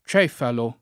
©$falo] s. m. (zool.) — es. con acc. scr.: prendessi due chili di cèfali al giorno! [